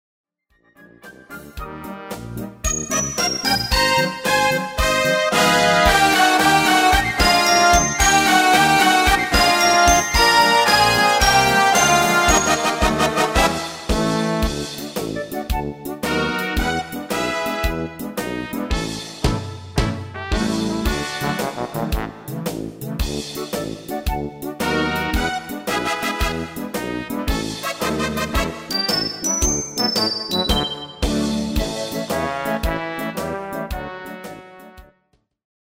Midifile gearrangeerd in de stijl van:
Genre: Nederlands amusement / volks
Demo's zijn eigen opnames van onze digitale arrangementen.